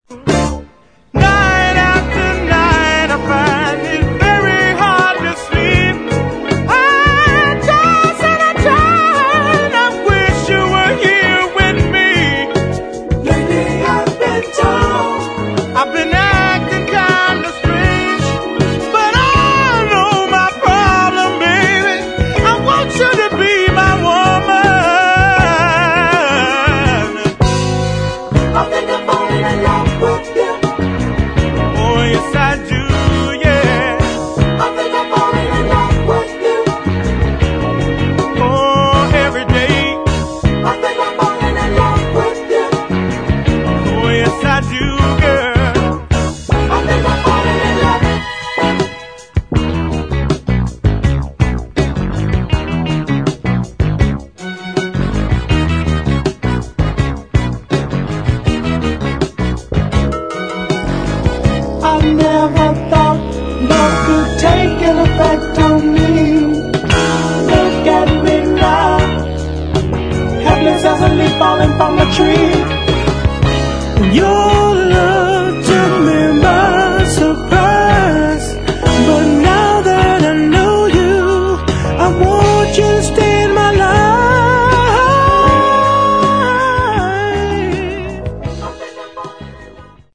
[ FUNK / SOUL / DISCO ]